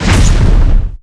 controller_first_hit.ogg